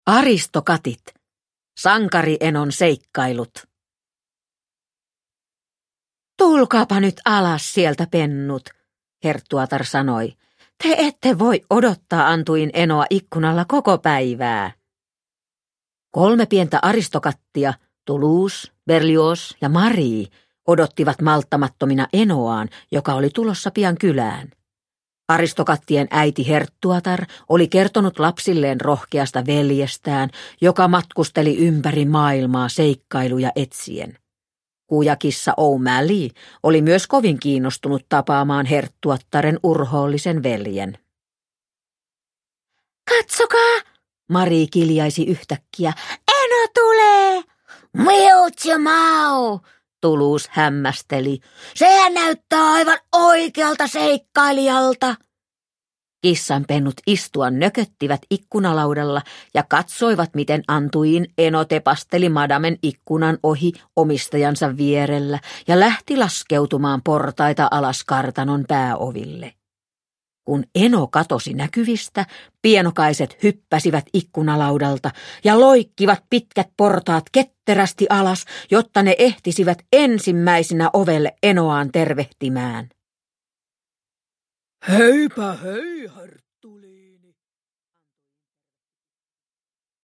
Aristokatit. Sankarienon seikkailut – Ljudbok – Laddas ner